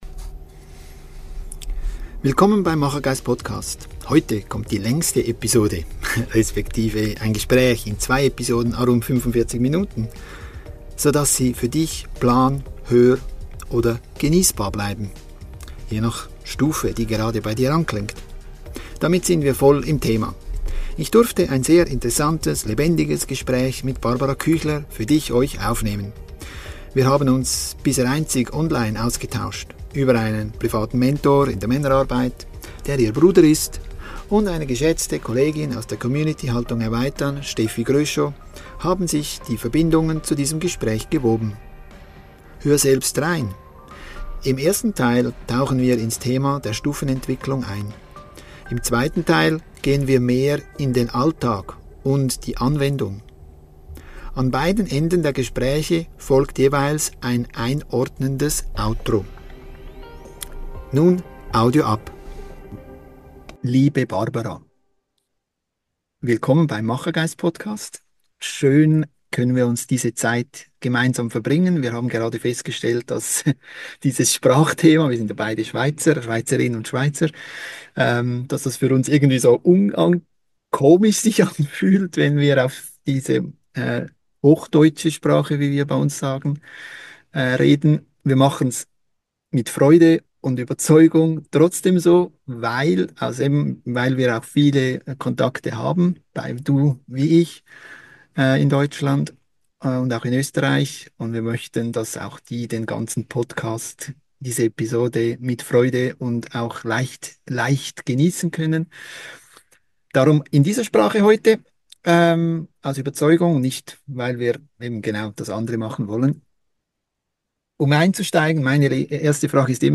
Die Intro's und Outro's führen dich durch das gesamte Gespräch.